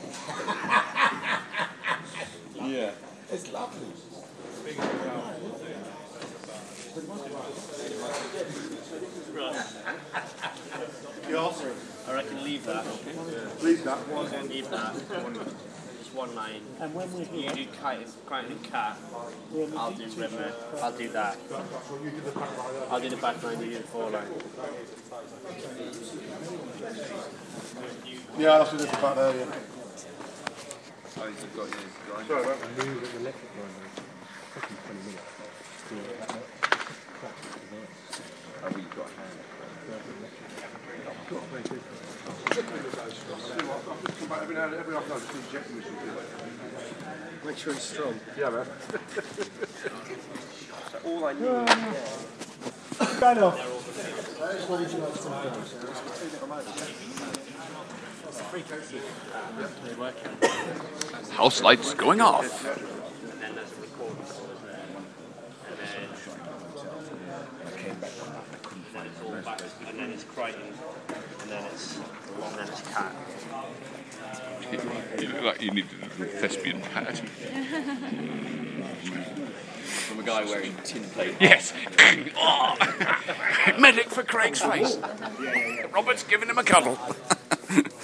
Red Dwarf Boo (recorded during camera rehearsal)